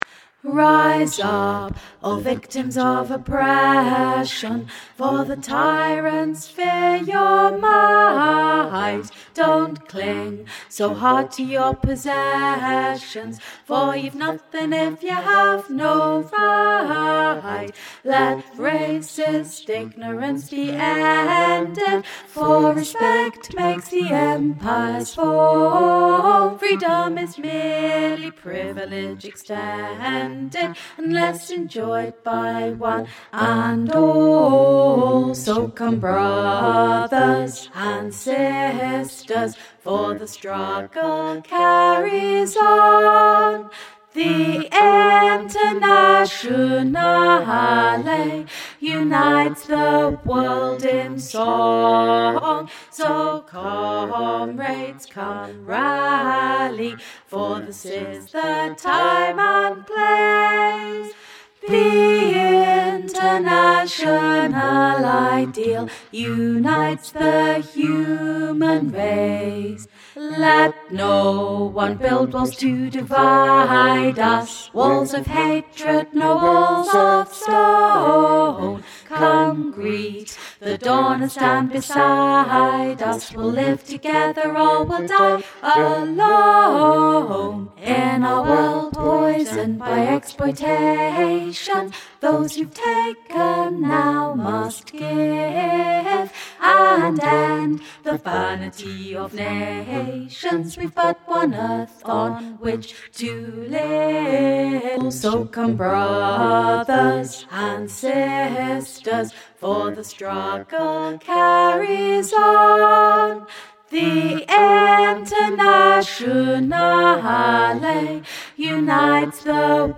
internationale_bass - Three Valleys Gospel Choir
internationale_bass.mp3